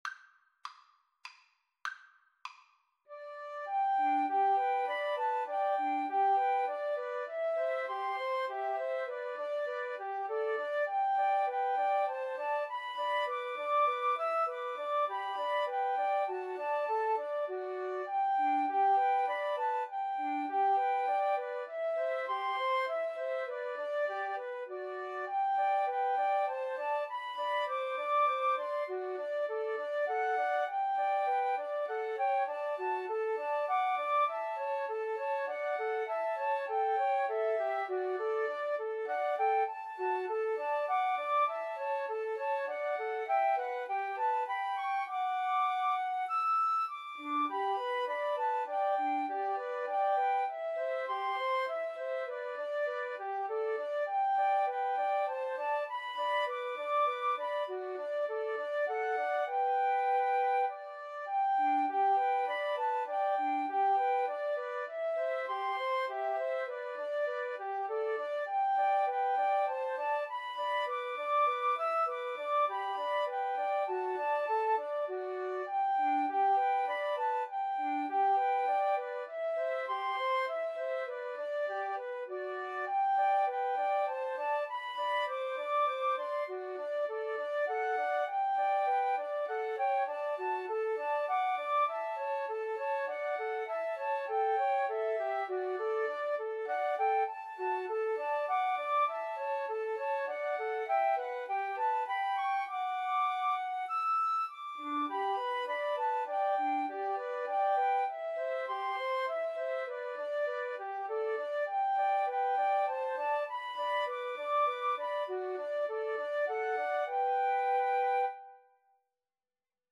G major (Sounding Pitch) (View more G major Music for Flute Trio )
Moderato
3/4 (View more 3/4 Music)
Flute Trio  (View more Easy Flute Trio Music)
Classical (View more Classical Flute Trio Music)